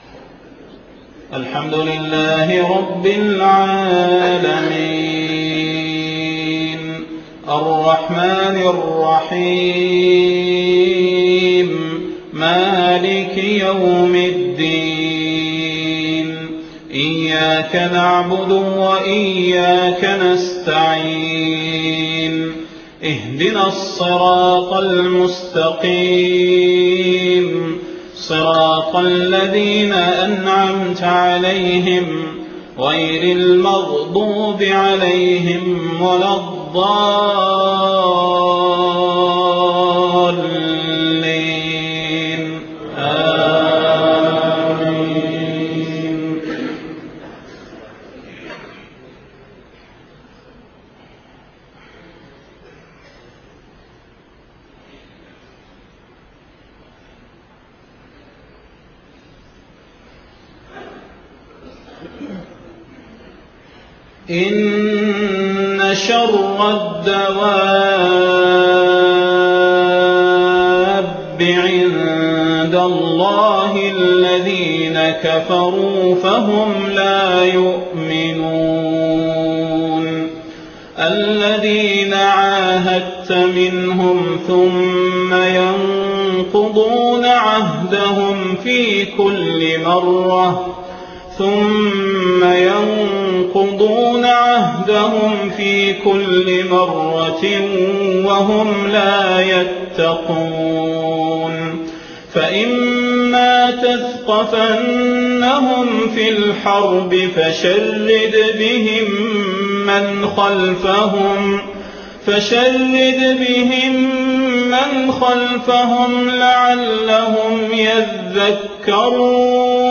صلاة المغرب 3 محرم 1430هـ من سورة الأنفال 55-60 > 1430 🕌 > الفروض - تلاوات الحرمين